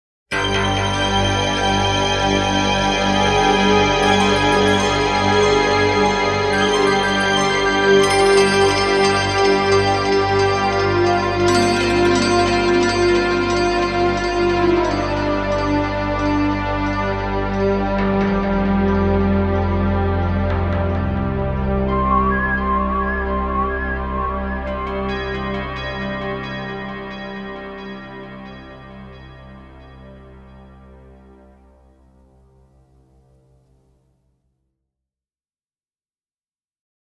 Orchestral Film Version